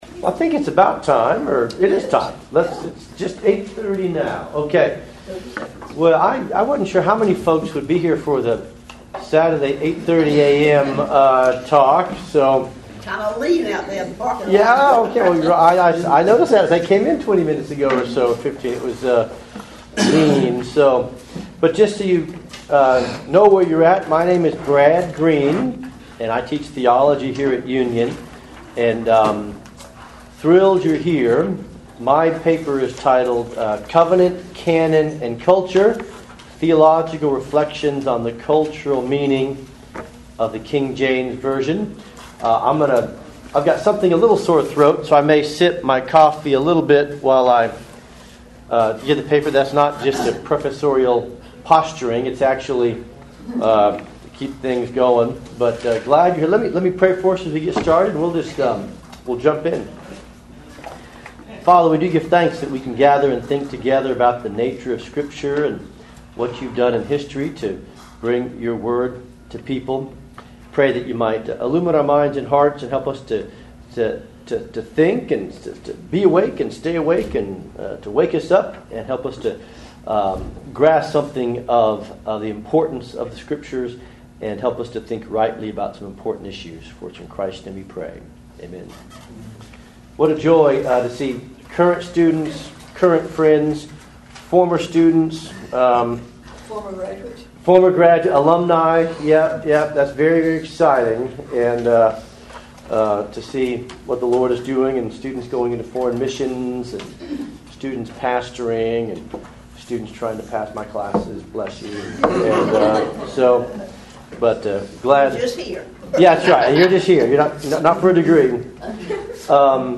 KJV400 Festival
Address: Covenant, Canon, and Culture: Theological Reflections on the Cultural Meaning of the KJV